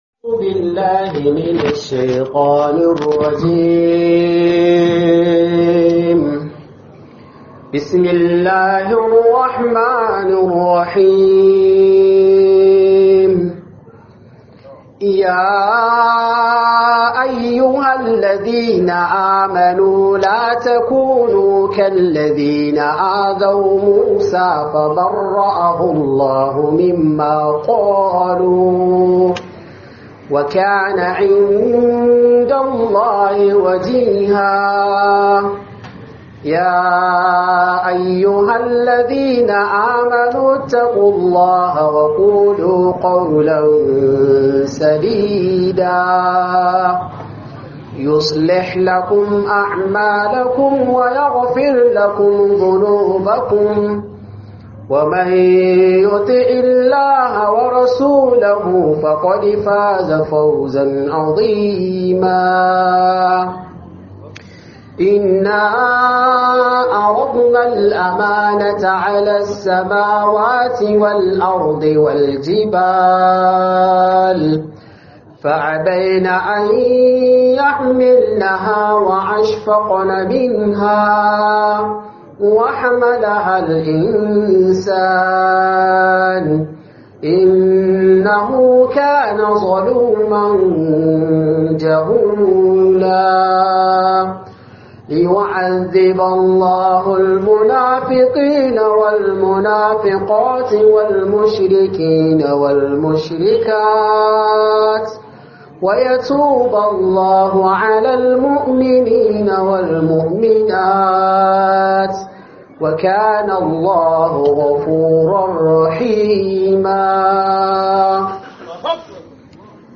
Illar Zina - Muhadara